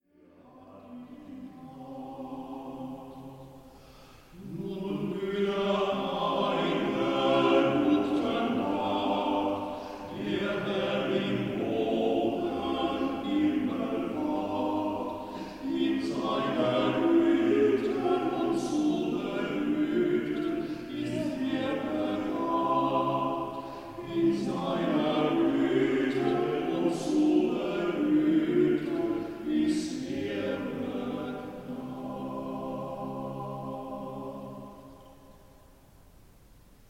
Kein schöner Land – Männerquartett, 4.Strophe